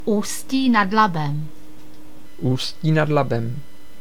Ústí nad Labem (Czech pronunciation: [ˈuːsciː nad ˈlabɛm]
Cs-Usti_nad_Labem.ogg.mp3